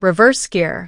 reverse_gear.wav